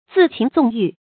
恣情纵欲 zì qíng zòng yù
恣情纵欲发音